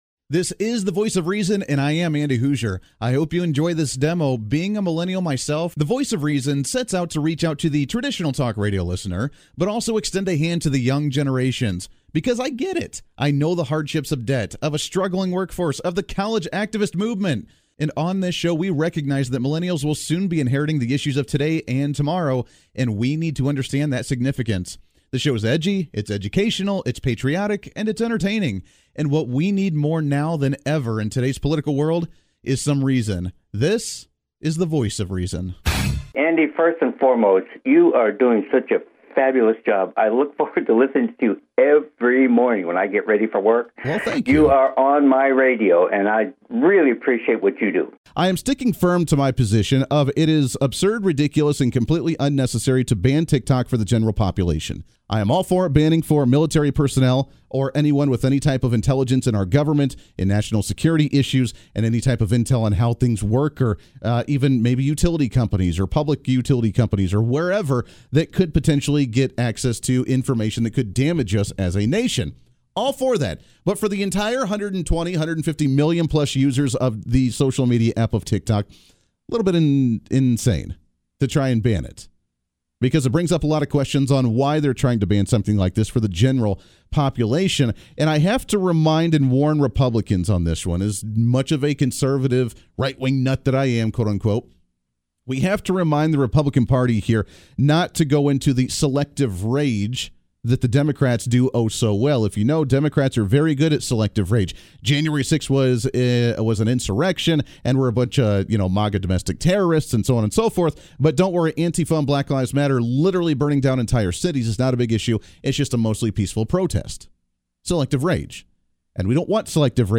Show Demo
The Voice of Reason is a nationally syndicated conservative talk show that focuses on activism at the local level. The show discusses current events while applying Constitutional principles, traditional values, and logic to the issues of today.